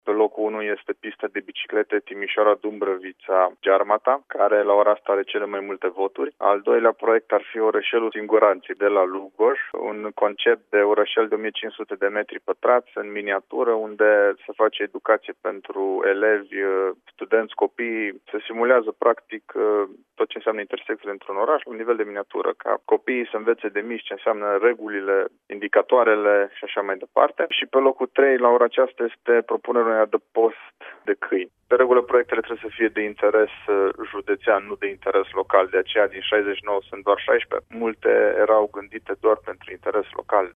Vicepreședintele CJ Timiș a explicat, la Radio Timișoara, că, deși au fost depuse aproape 70 de proiecte, cele mai multe nu au fost eligibile, pentru că aveau un impact local, nu județean.